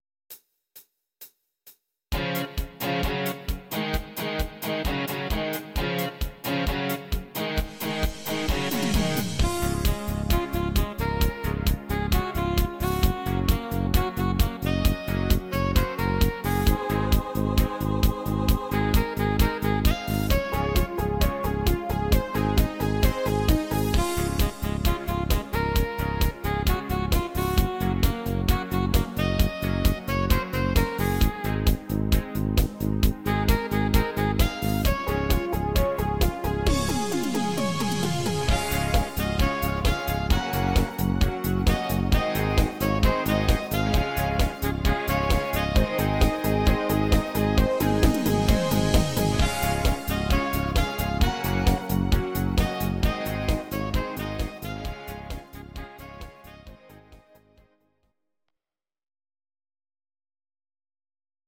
These are MP3 versions of our MIDI file catalogue.
Please note: no vocals and no karaoke included.
Discofox Version deutsch